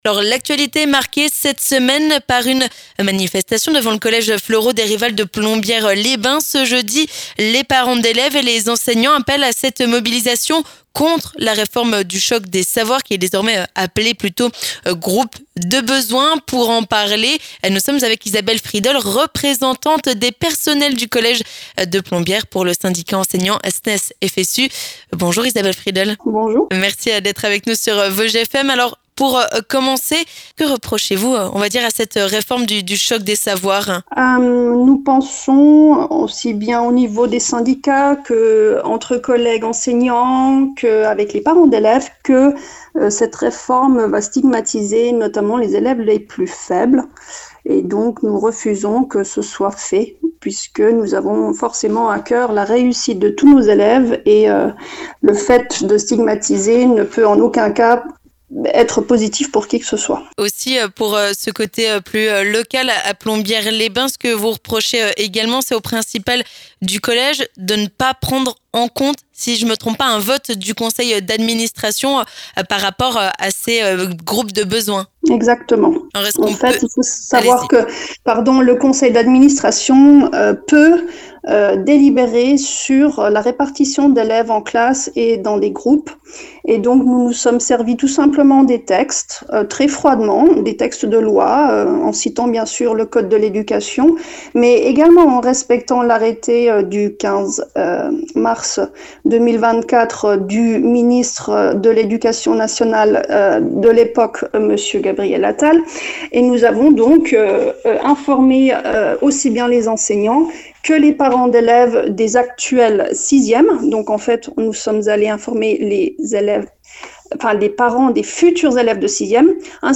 On en parle avec